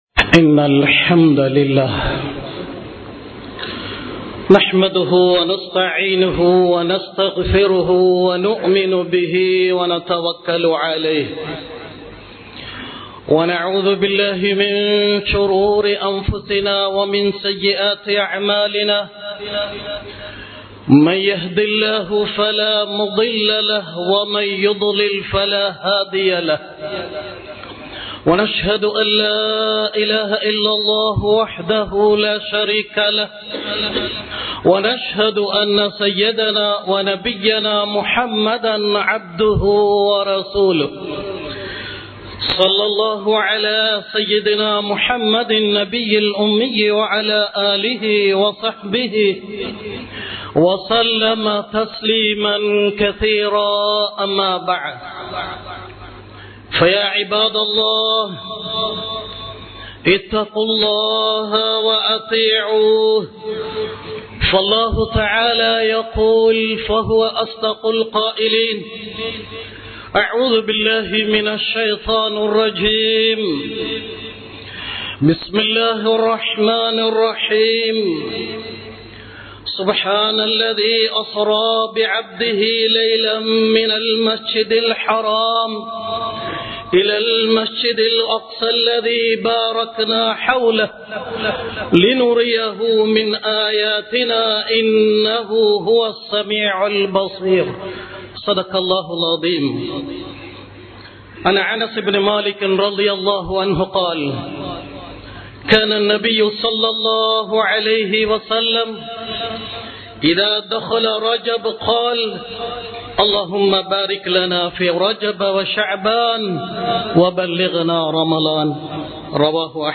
இஸ்லாம் கூறும் வெற்றியாளர்கள் | Audio Bayans | All Ceylon Muslim Youth Community | Addalaichenai
Majma Ul Khairah Jumua Masjith (Nimal Road)